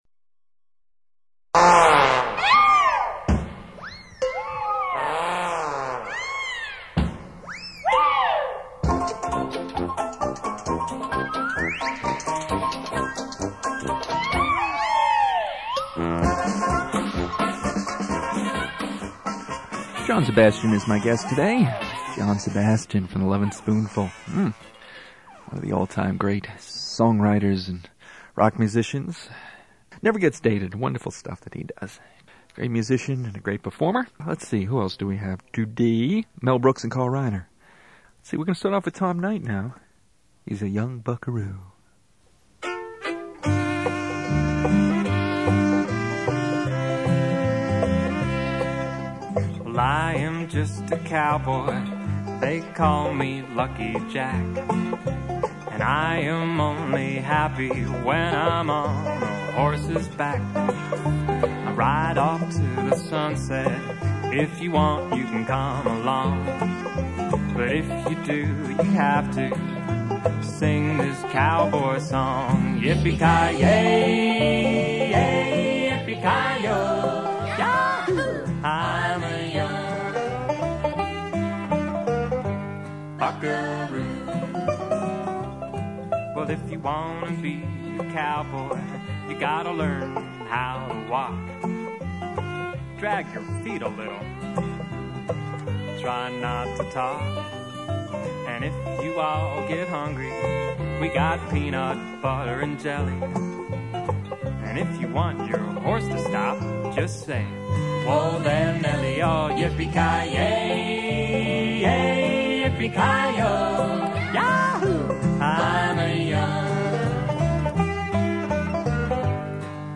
Knock On Wood Comedy Show
John Sebastian is a guest.